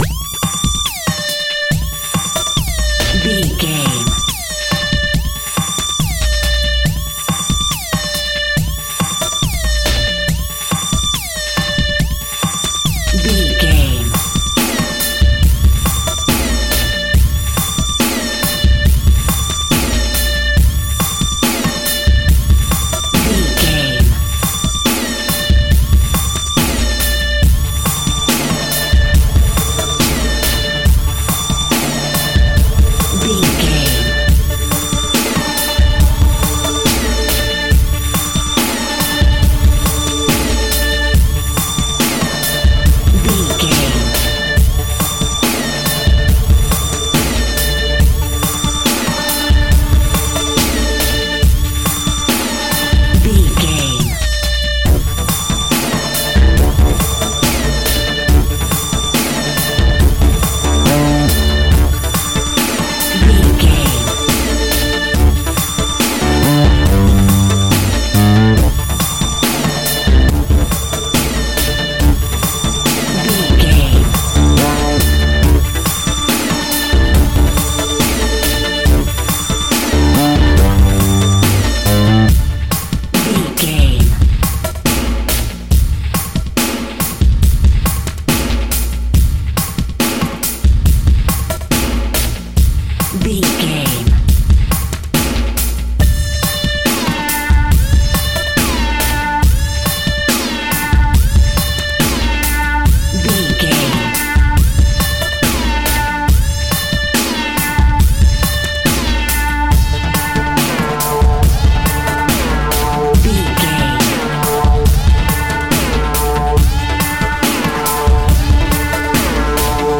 Aeolian/Minor
D
futuristic
hypnotic
industrial
mechanical
dreamy
frantic
aggressive
powerful
drum machine
percussion
synthesiser
breakbeat
energetic
pumped up rock
electronic drums
synth lead
synth bass